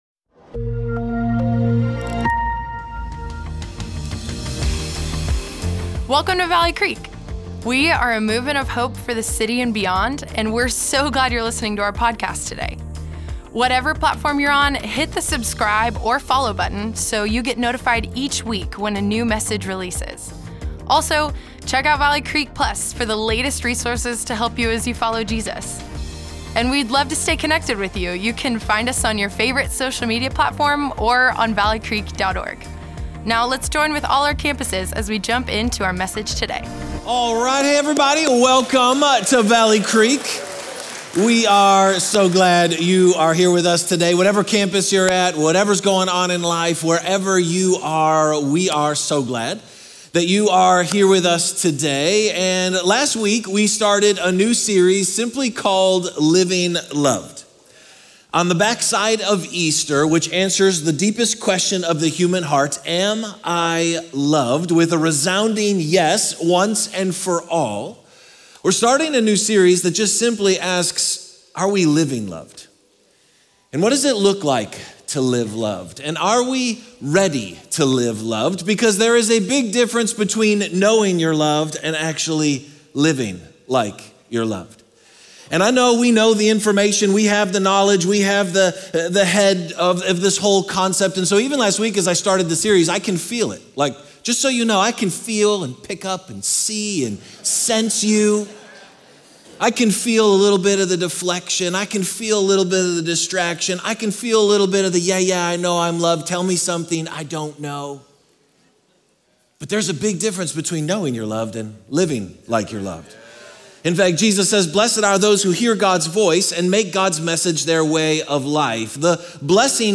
Valley Creek Church Weekend Messages Free From Performance May 04 2025 | 00:58:03 1x 00:00 / 00:58:03 Subscribe Share Apple Podcasts Spotify Amazon Music Overcast RSS Feed Share Link Embed